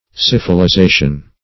Search Result for " syphilization" : The Collaborative International Dictionary of English v.0.48: Syphilization \Syph`i*li*za"tion\, n. (Med.)